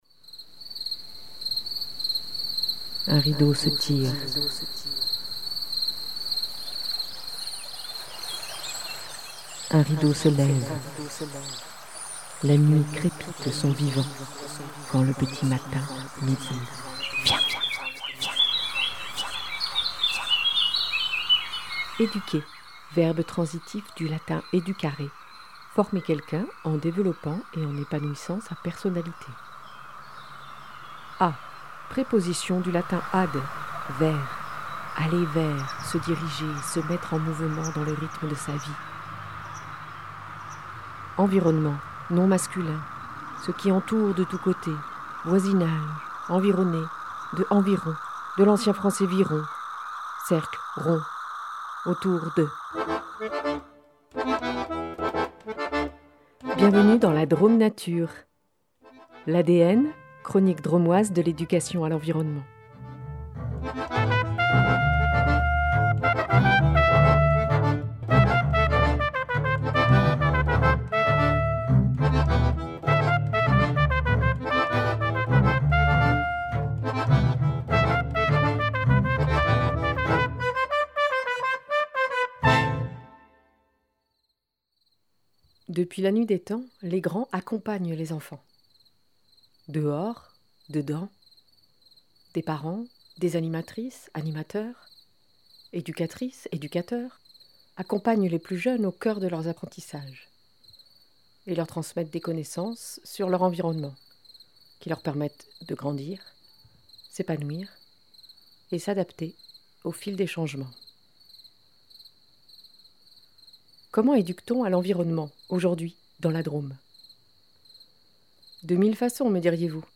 Émission Drômoise de l’Éducation à l’Environnement.